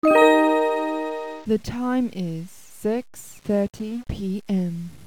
ClockDummy! uses a real human voice, not a synthesized computer voice,
to announce the time. Hear the sample female voice by clicking